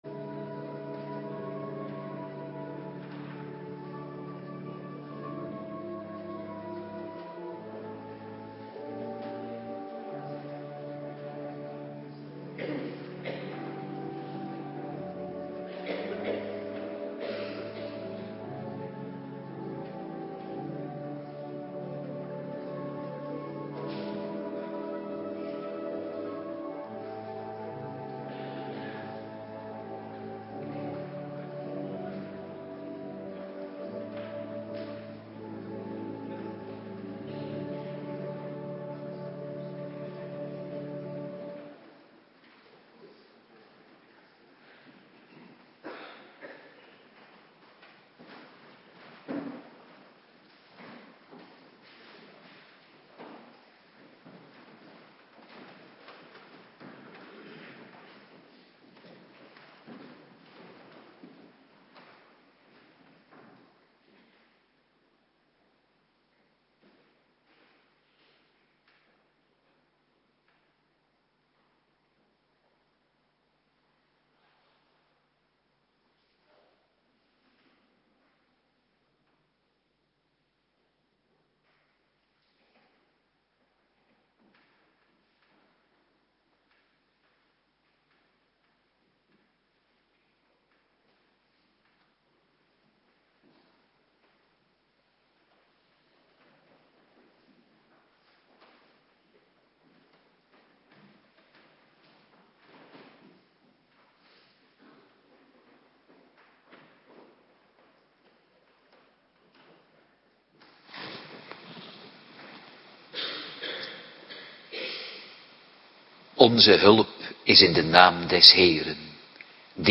Woensdagavonddienst
19:30 t/m 21:00 Locatie: Hervormde Gemeente Waarder Agenda